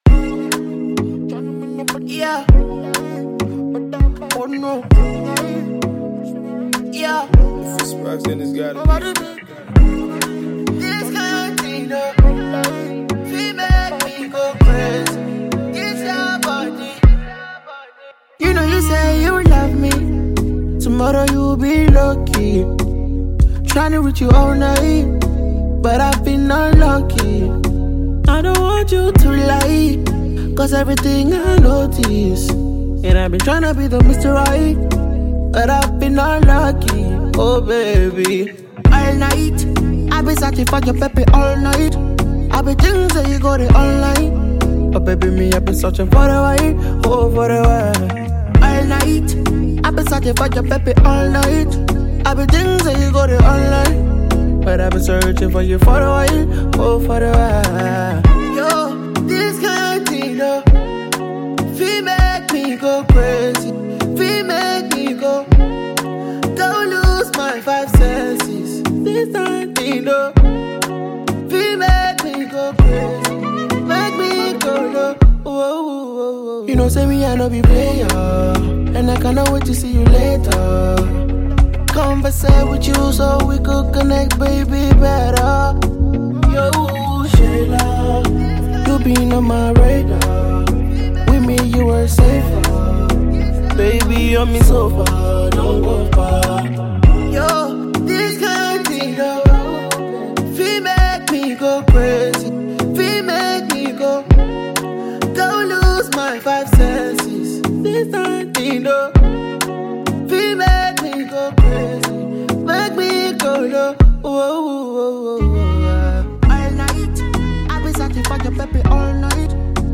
Afrobeats prospect